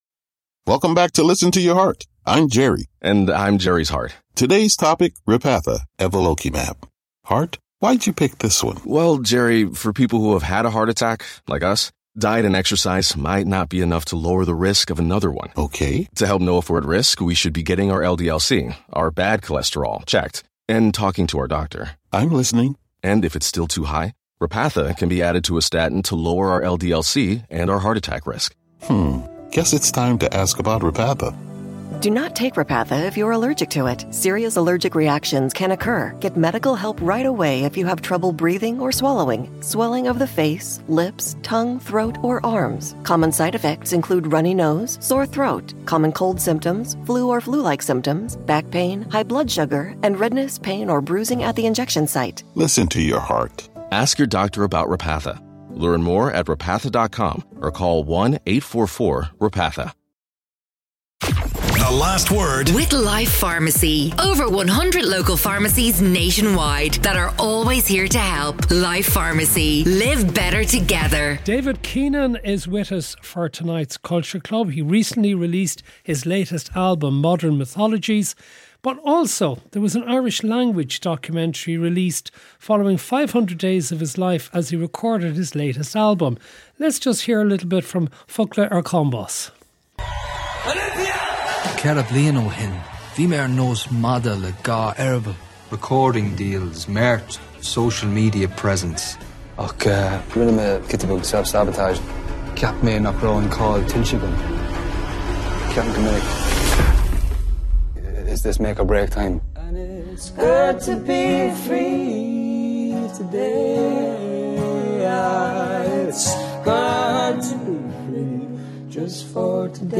On Ireland's most cutting edge current affairs show, Matt and his guests provide a running stream of intelligent opinions and heated debates on the issues that matter most to Irish listeners.